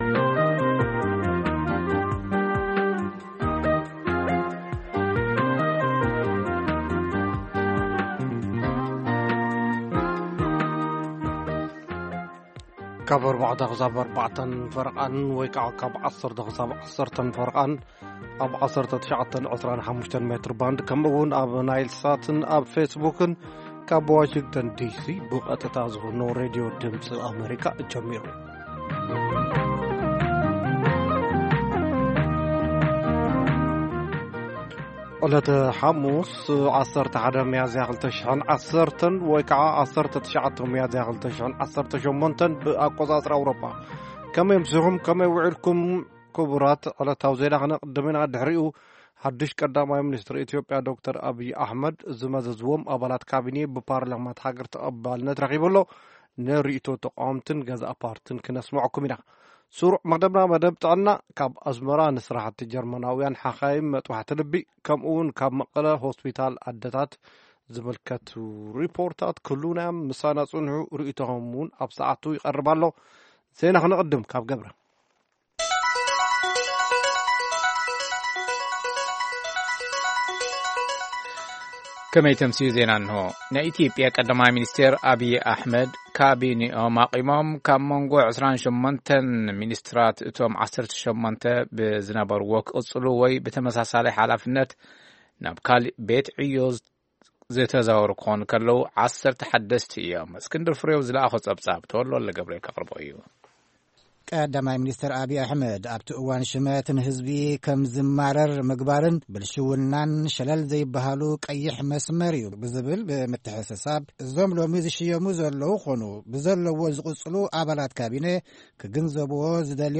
ፈነወ ትግርኛ ብናይ`ዚ መዓልቲ ዓበይቲ ዜና ይጅምር ። ካብ ኤርትራን ኢትዮጵያን ዝረኽቦም ቃለ-መጠይቓትን ሰሙናዊ መደባትን ድማ የስዕብ ። ሰሙናዊ መደባት ሓሙስ፡ መንእሰያት/ ጥዕና